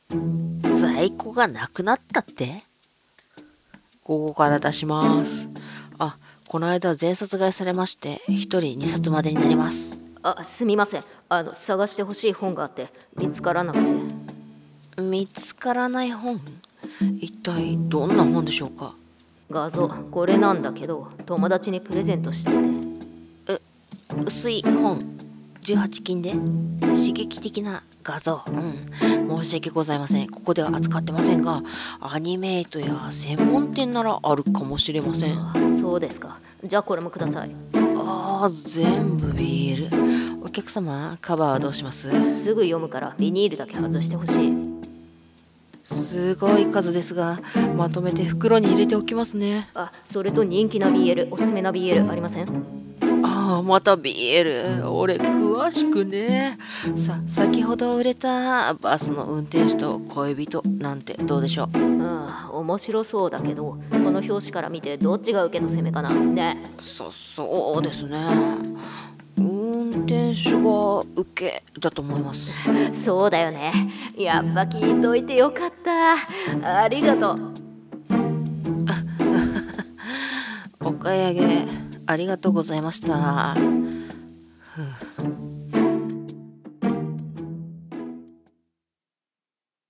声劇 BL買うお客様 ギャグ 企画台本